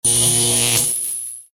electricity.ogg